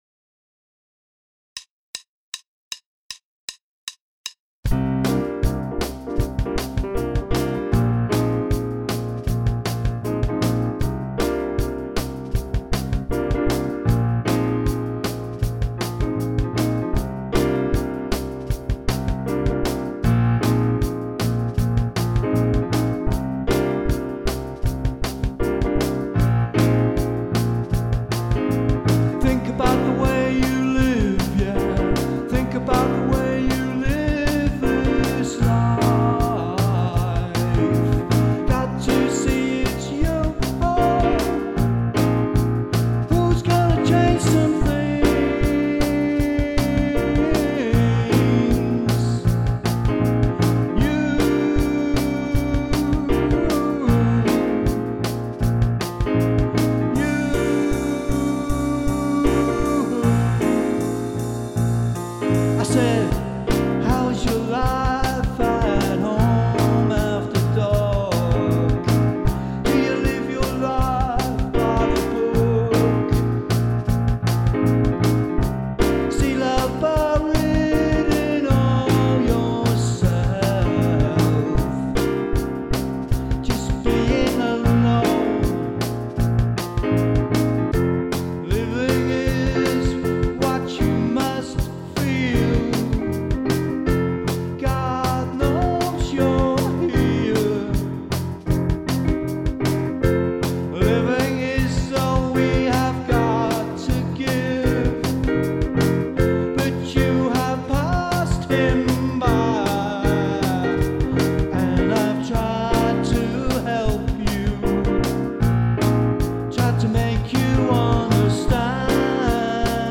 6:37/156bpm